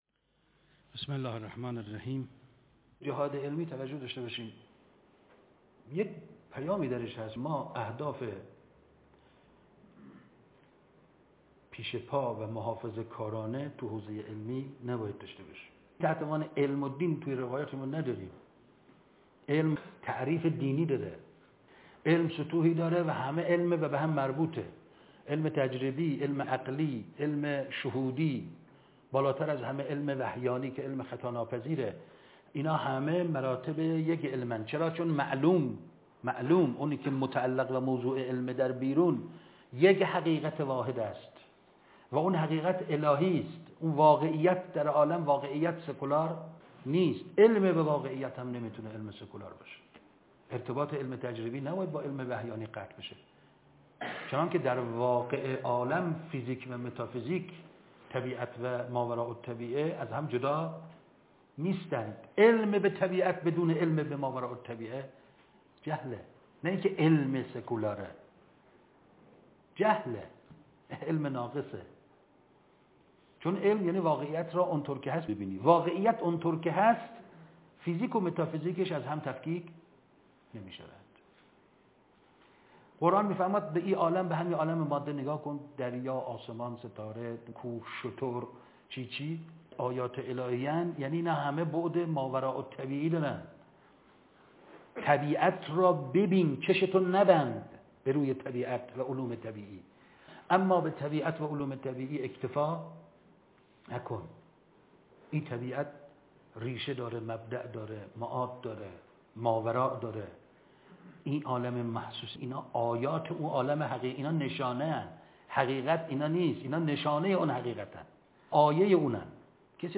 دانشگاه امام صادق ع _ ۱۳۹۳